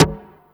45 SD 2   -L.wav